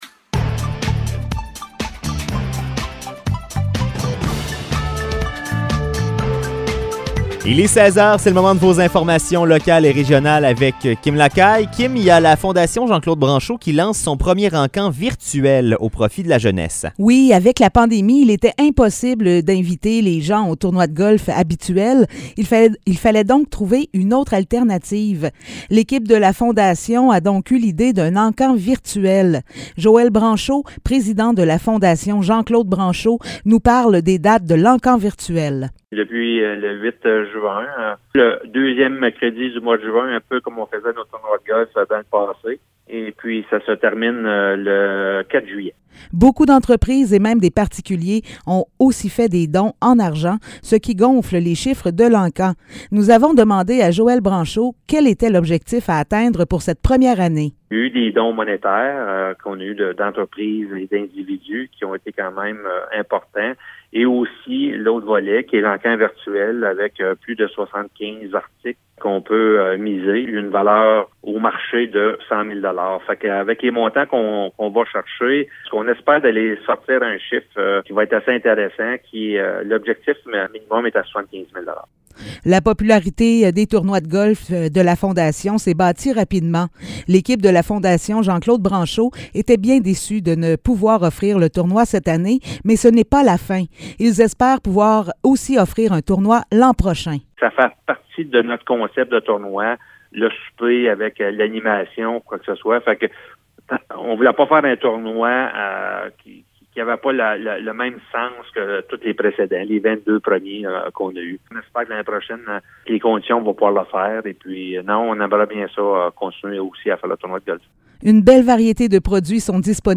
Nouvelles locales - 14 juin 2022 - 16 h